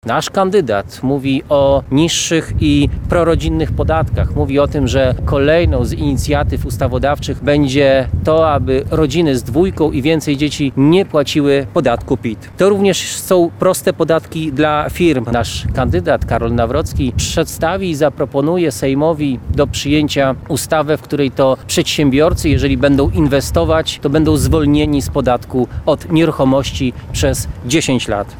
– Jedną z pierwszych inicjatyw Karola Nawrockiego jako prezydenta będzie przedłożenie Sejmowi ustawy o obniżeniu stawek VAT do 22%, są też inne propozycje dotyczące podatków – mówi poseł Prawa i Sprawiedliwości Sylwester Tułajew.